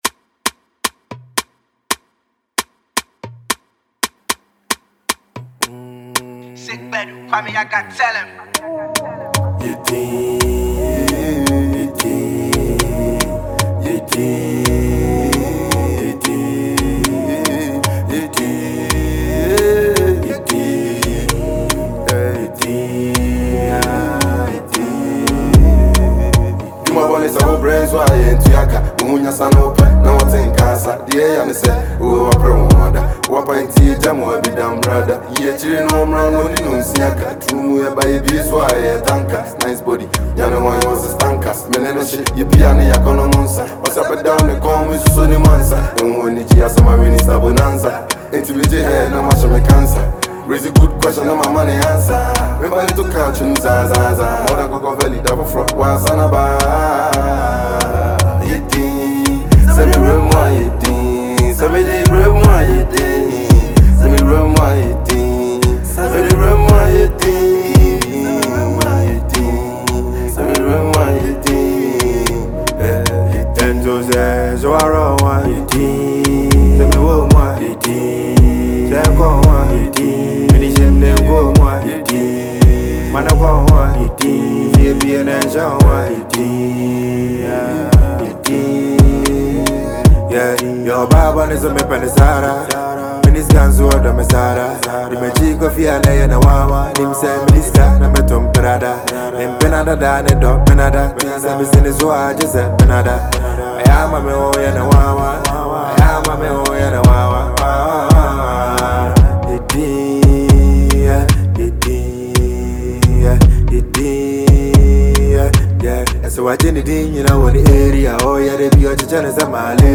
a Ghanaian rapper
asakaa